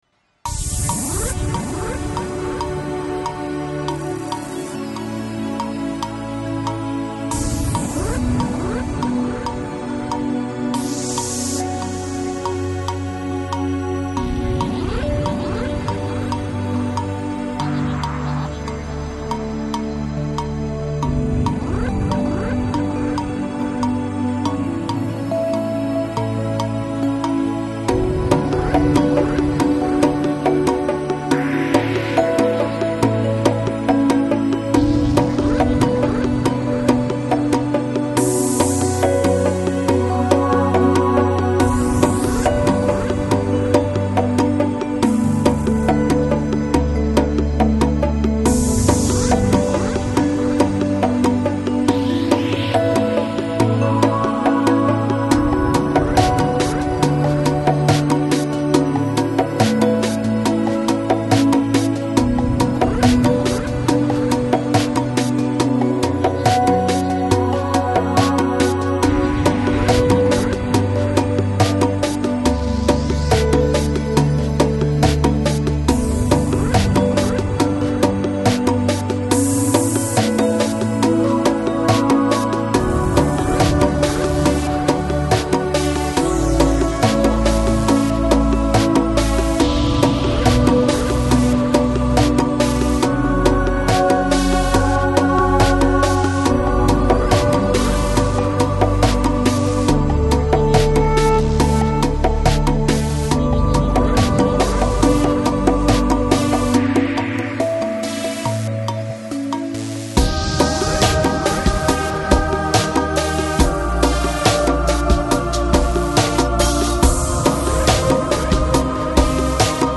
Жанр: Lounge, Chill Out, Lo Fi